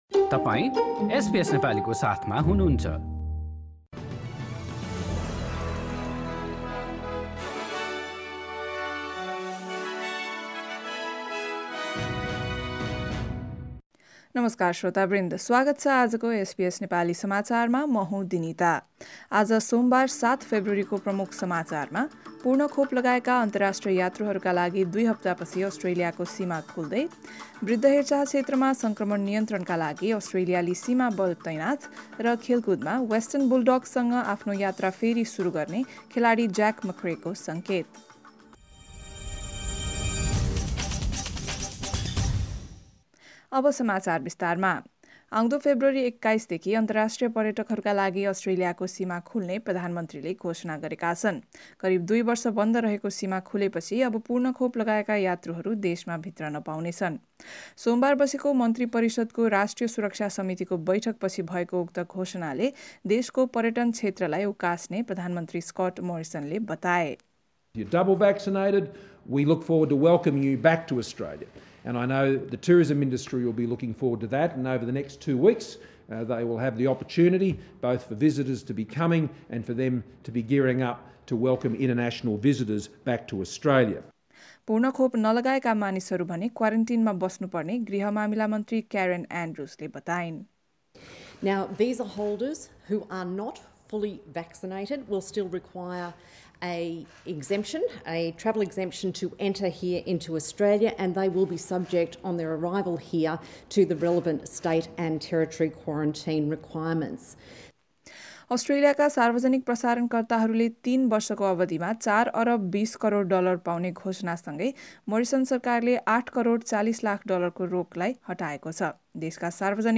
एसबीएस नेपाली अस्ट्रेलिया समाचार: सोमबार ७ फेब्रुअरी २०२२